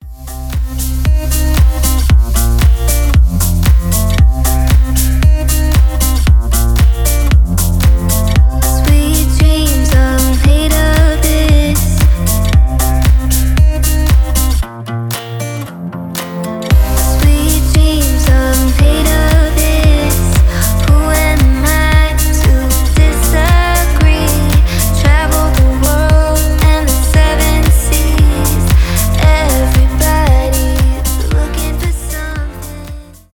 cover , chill house , deep house